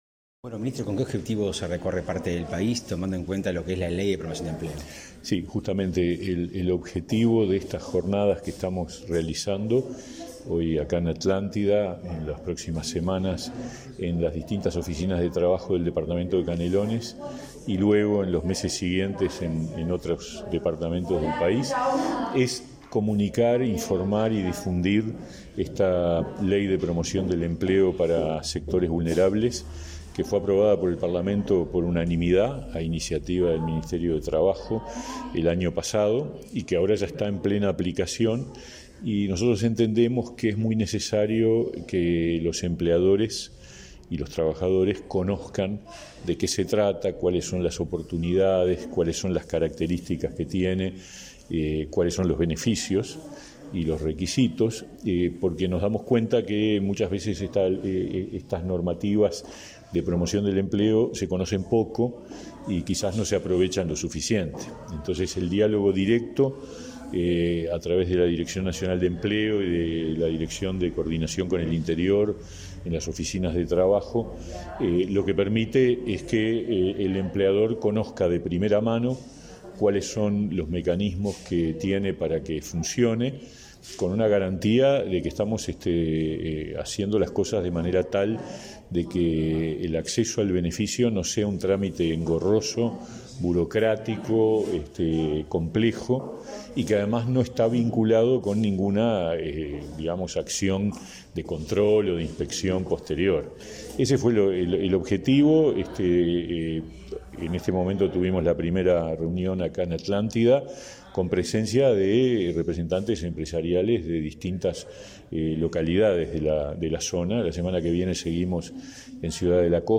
Palabras del ministro de Trabajo y Seguridad Social, Pablo Mieres
Palabras del ministro de Trabajo y Seguridad Social, Pablo Mieres 03/08/2022 Compartir Facebook X Copiar enlace WhatsApp LinkedIn El Ministerio de Trabajo inició encuentros para difundir alcances de la Ley de Promoción del Empleo. Este 3 de agosto, el ministro Pablo Mieres se reunió con empresarios en Atlántida para difundir sus objetivos y beneficios de la normativa.